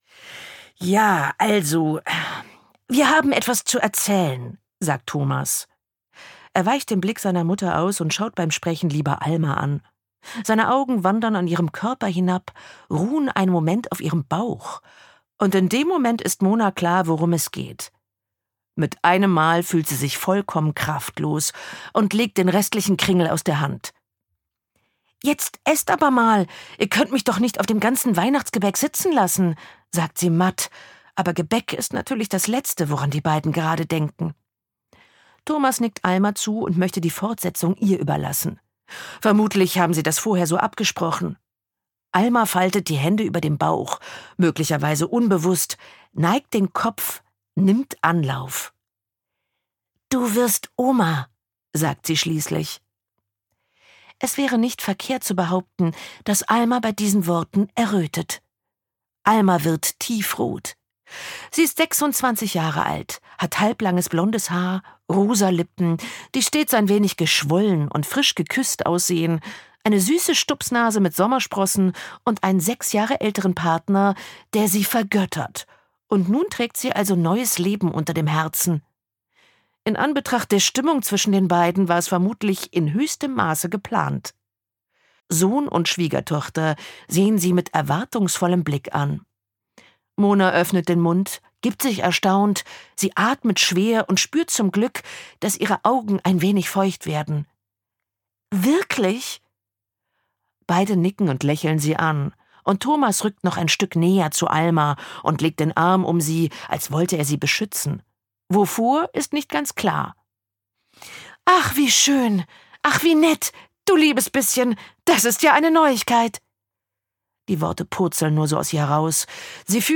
Evil Grandma - Line Baugstø | argon hörbuch
Gekürzt Autorisierte, d.h. von Autor:innen und / oder Verlagen freigegebene, bearbeitete Fassung.